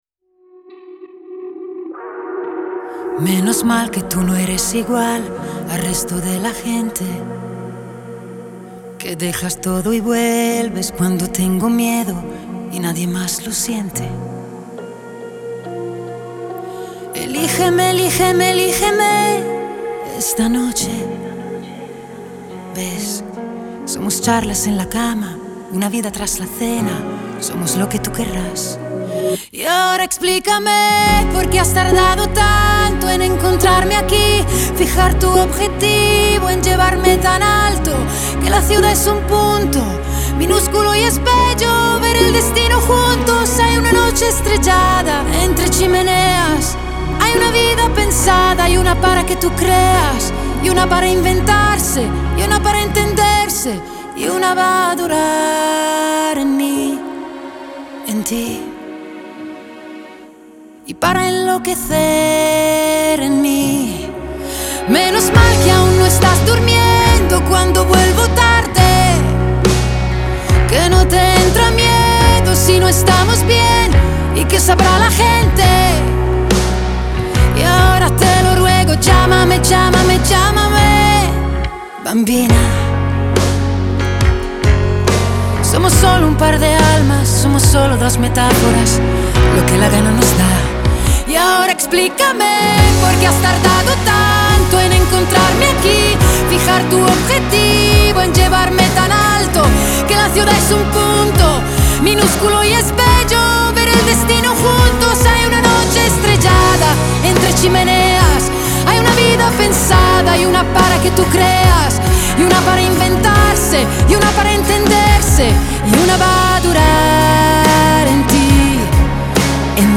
Genre : Latin America